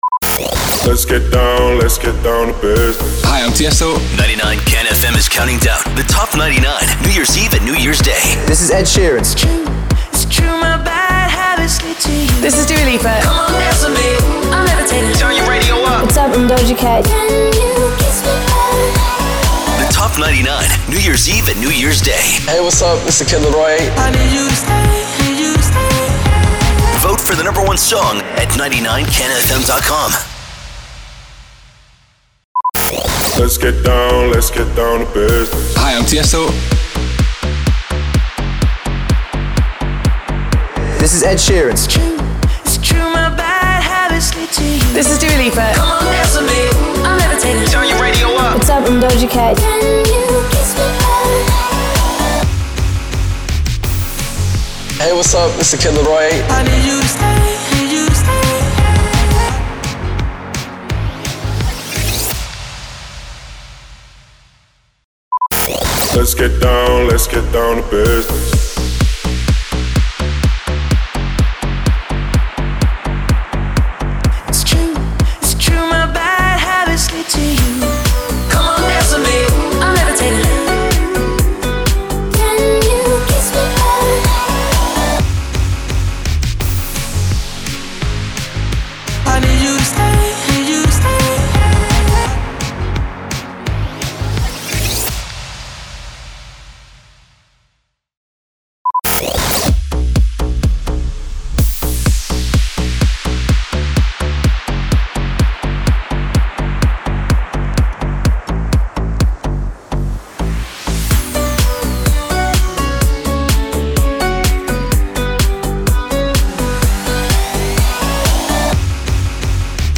346 – SWEEPER – TOP 99 MASH UP PROMO
346-SWEEPER-TOP-99-MASH-UP-PROMO.mp3